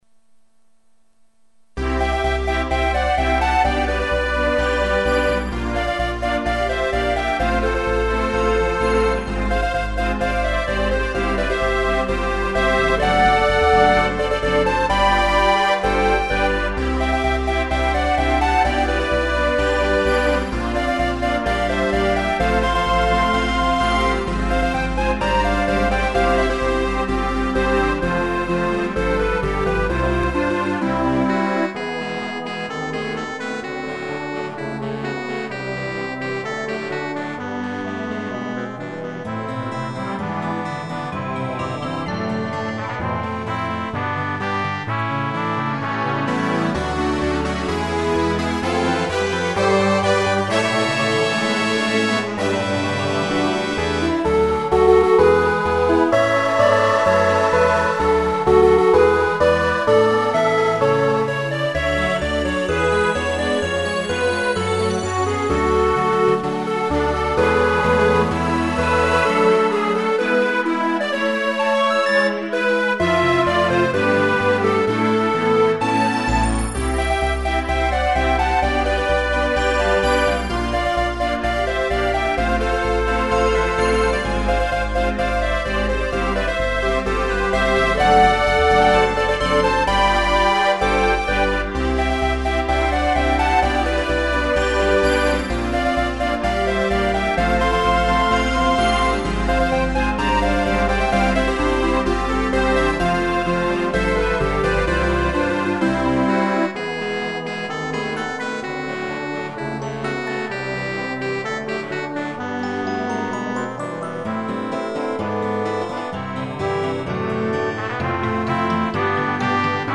RPGっぽい曲。ドラムなし。